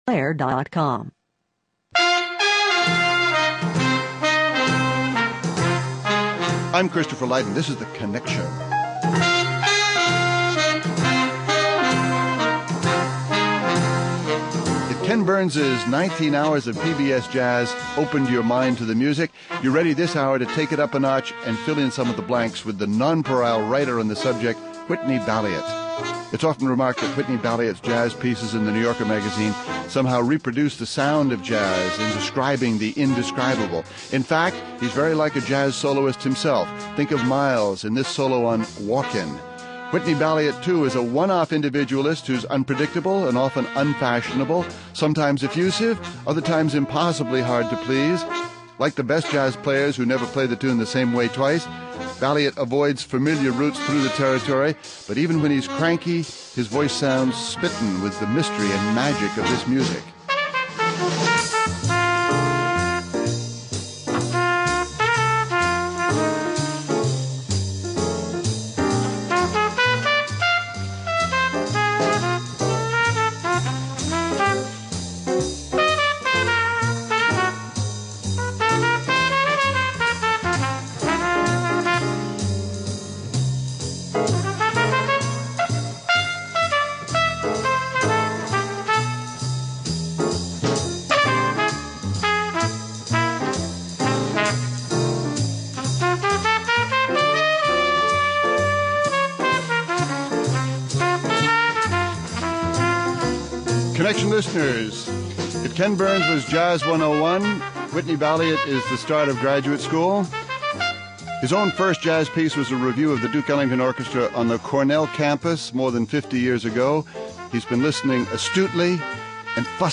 Join us for Whitney Balliett’s running history of what he calls “the sound of surprise.” (Hosted by Christopher Lydon)